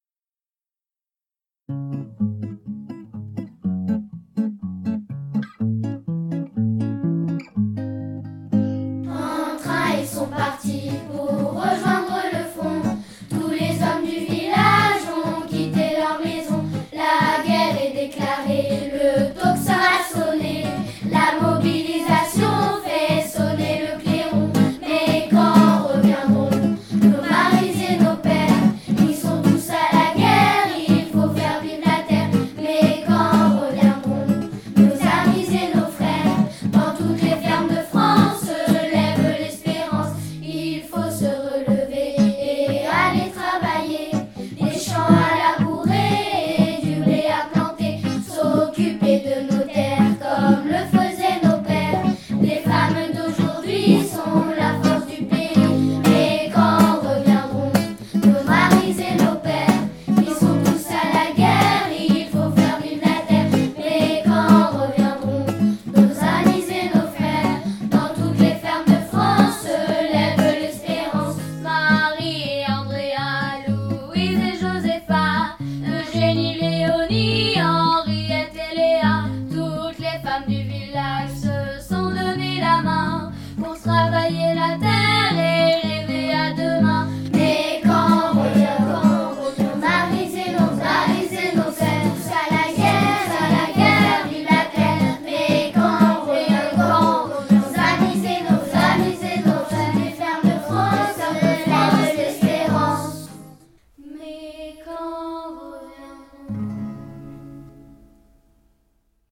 Le 9 novembre prochain à 18h30, les élèves de CM2 vous proposeront un concert “Entre chants et champs”.
Un concert pour commémorer le centenaire de la fin de la première guerre mondiale.